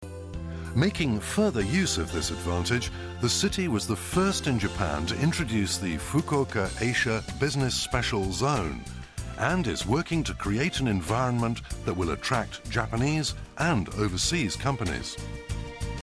注：音声データは、DVD「DISCOVER Fukuoka City」の英語リスニング教材